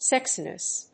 /ˈsɛksinʌs(米国英語), ˈseksi:nʌs(英国英語)/